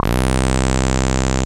FUNK SYNTH 1.wav